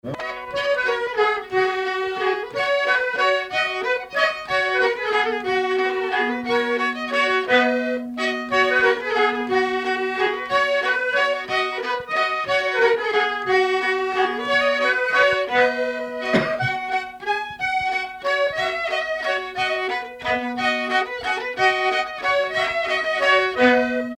danse : marche
circonstance : fiançaille, noce
Pièce musicale inédite